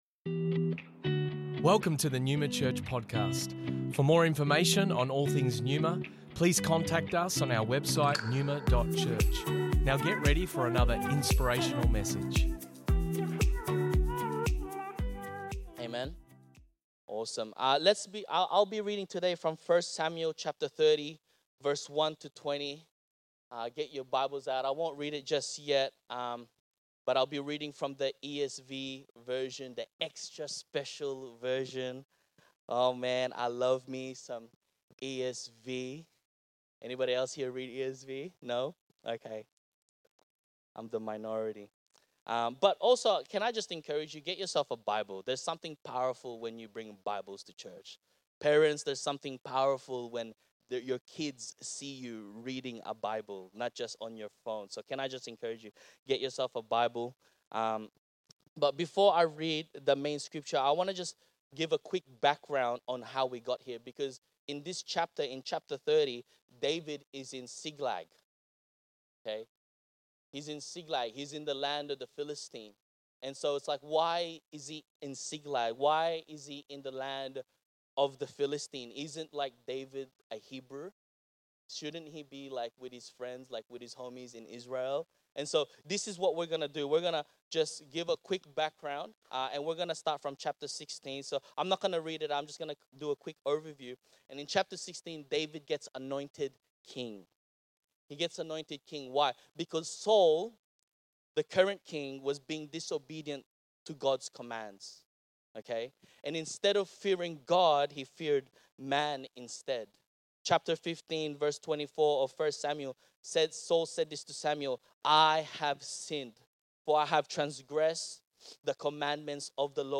Originally recorded at Neuma Melbourne West 15th October 2023